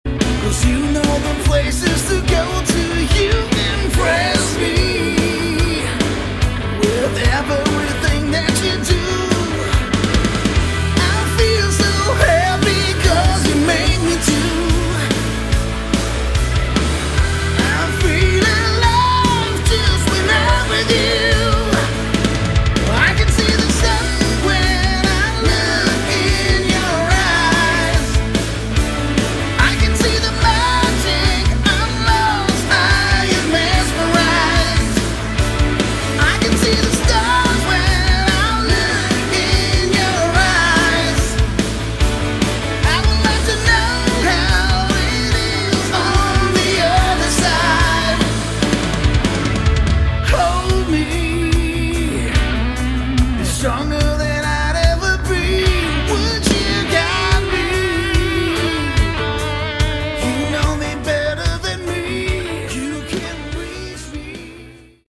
Category: Hard Rock
Vocals
Keyboards
Bass
Drums
Guitar